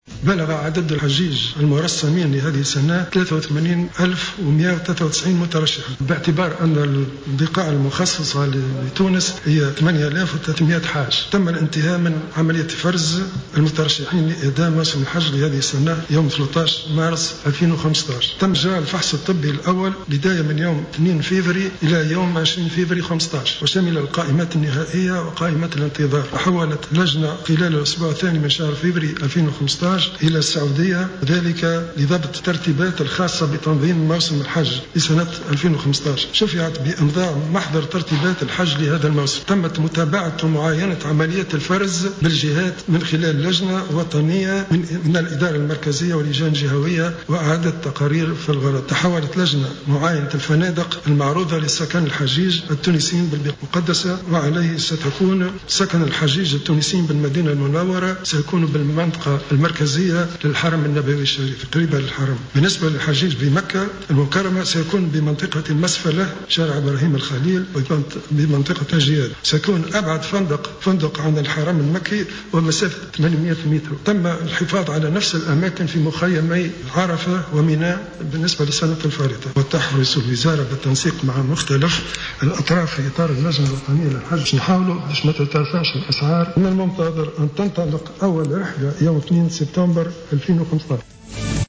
أعلن وزير الشؤون الدينية عثمان بطيخ خلال ندوة صحفية عقدها اليوم الاثنين 20 أفريل 2015 بقصر الحكومة بالقصبة أن يوم 2 سبتمبر 2015 سيكون تاريخ أول رحلة للحجيج التونسيين إلى البقاع المقدسة.